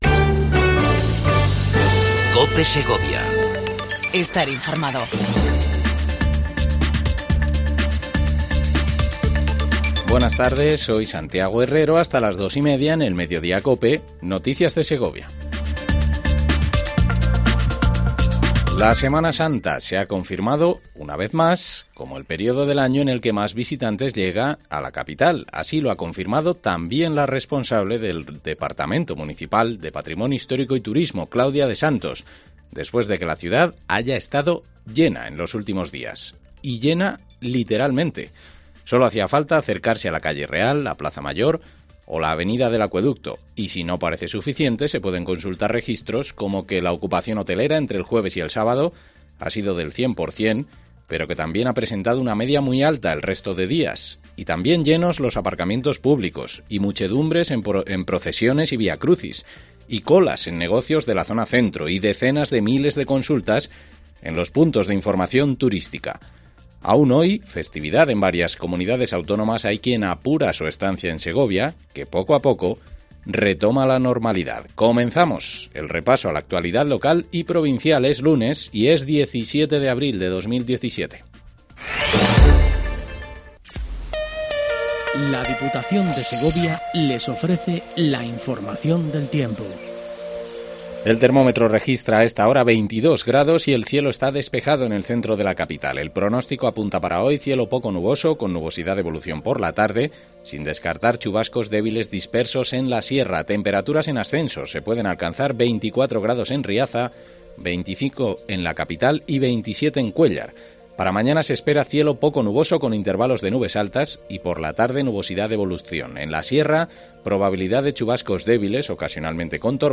INFORMATIVO MEDIODIA COPE EN SEGOVIA 17 04 17